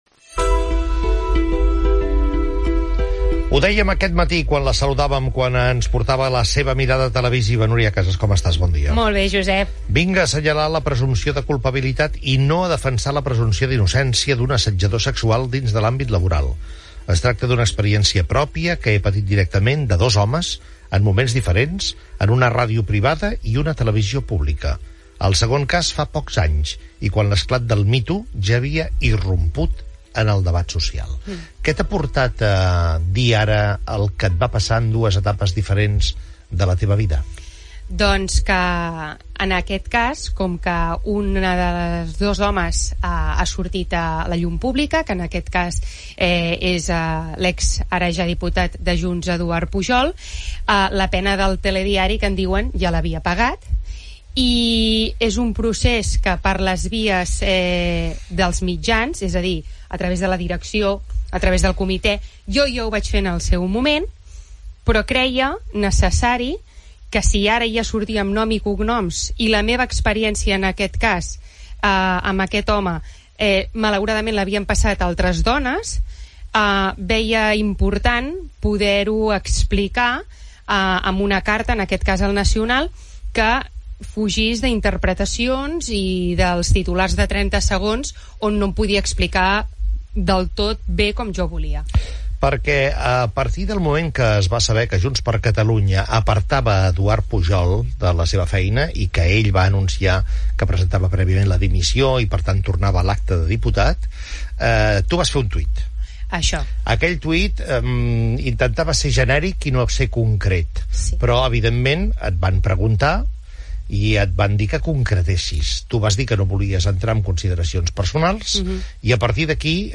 Entrevista
Indicatiu del programa.
Info-entreteniment